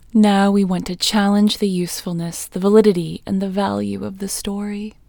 OUT Technique Female English 21